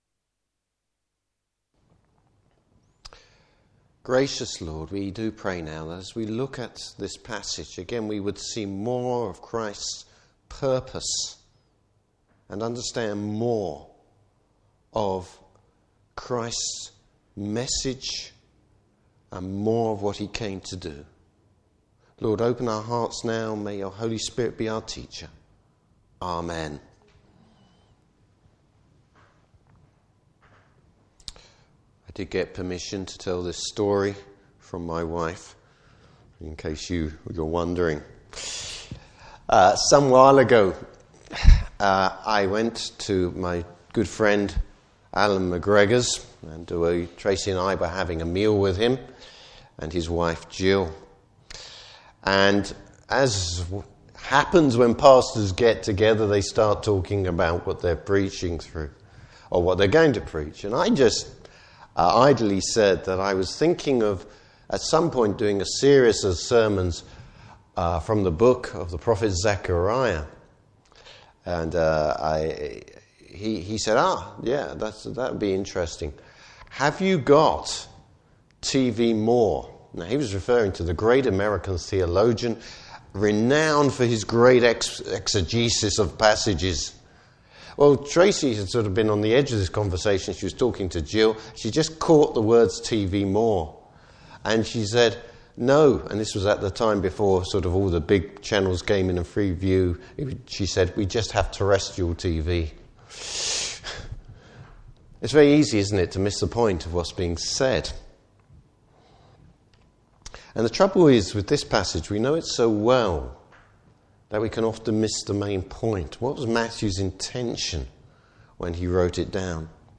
Service Type: Morning Service How Christ’s reaction to temptation is offensive and not defensive.